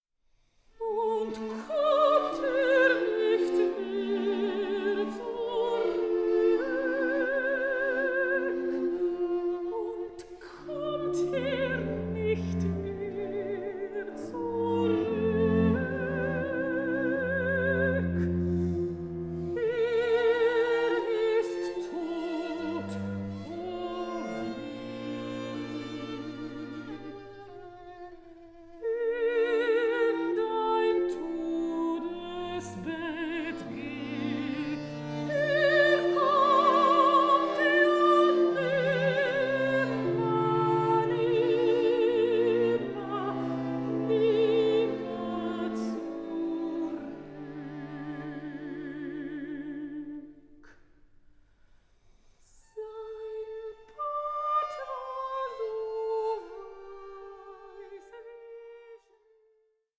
AN EVENING OF INTIMATE SONGS AMONG FRIENDS
mezzo-soprano